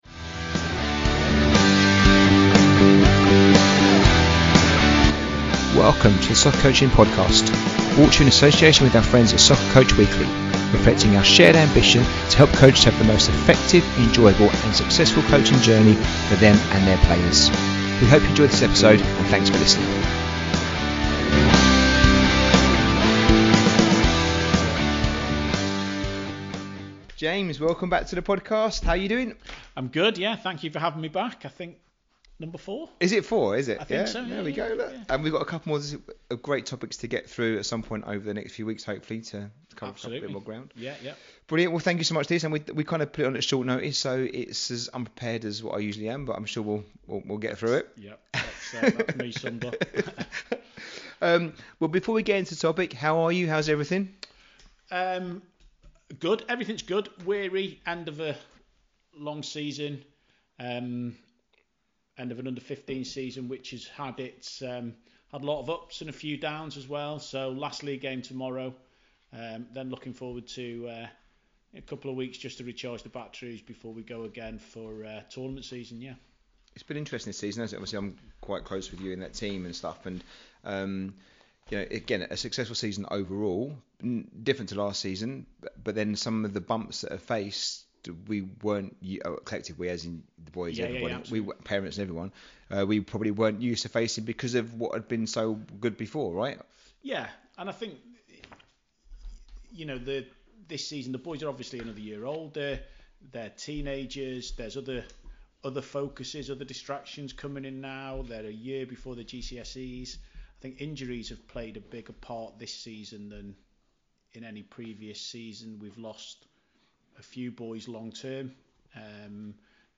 Episode 109 - Whats too far? A conversation about behaviour in youth grassroots football